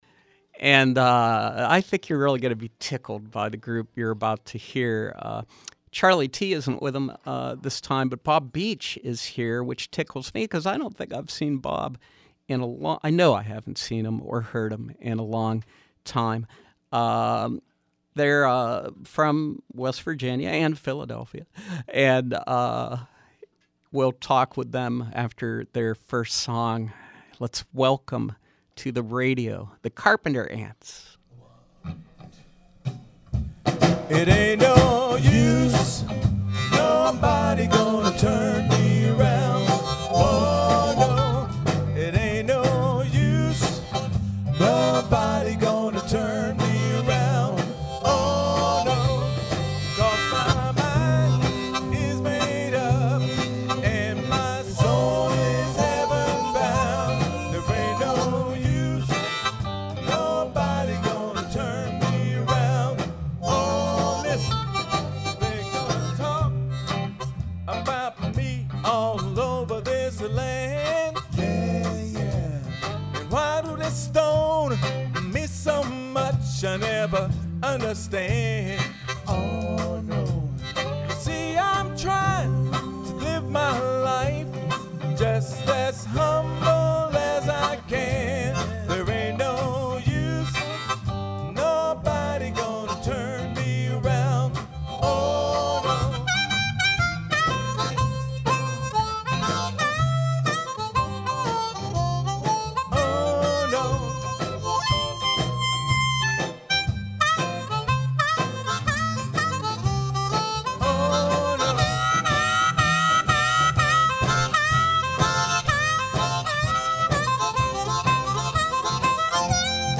Old-style rhythm and blues, gospel soul and country funk